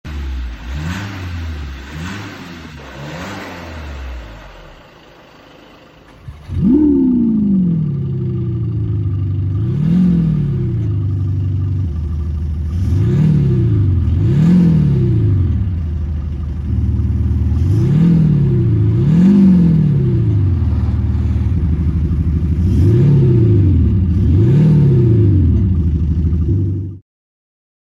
Audi vor und nach der Montage vom Sound Booster🔥